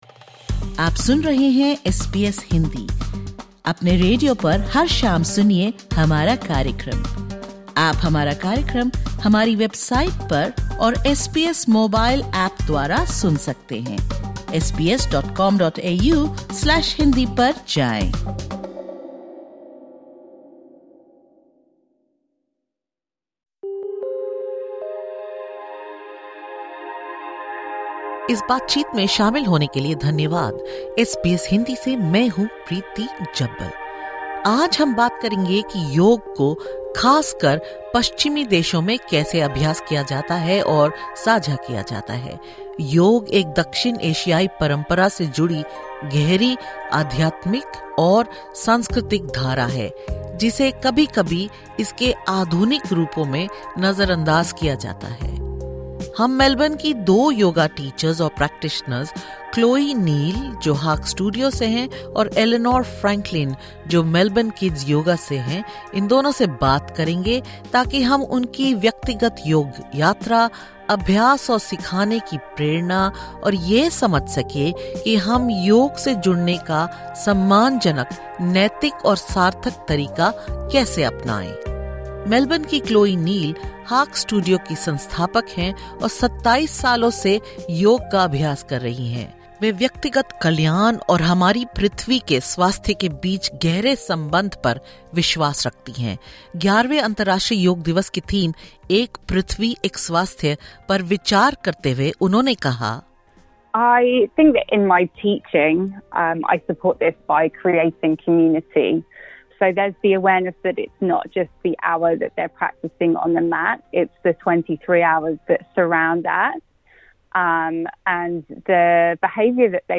In this segment, SBS Hindi engages in a conversation with yoga teachers from diverse backgrounds about how they strive to honour yoga as more than just a modern trend, recognizing and respecting its deep roots in history, culture, and holistic tradition.